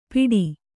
♪ piḍi